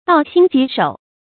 悼心疾首 dào xīn jí shǒu 成语解释 心里伤感，头部疼痛。
ㄉㄠˋ ㄒㄧㄣ ㄐㄧˊ ㄕㄡˇ